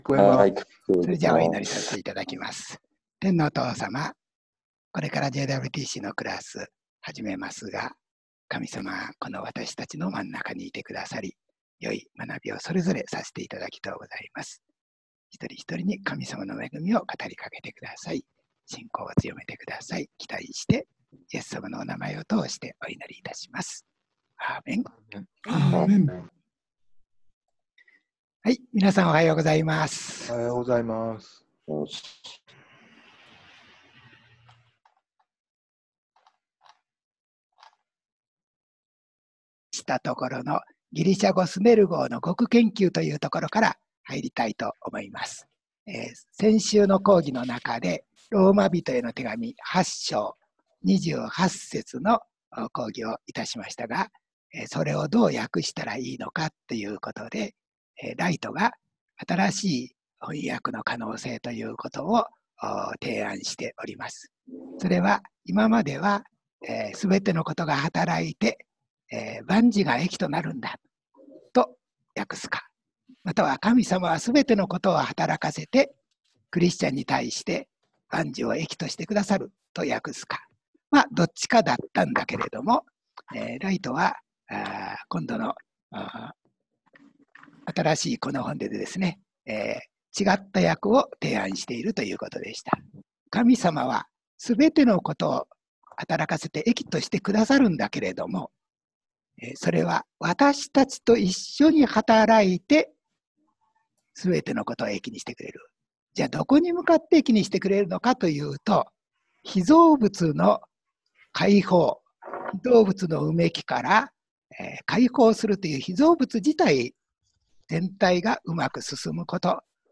２０２０年７月１日の講義内容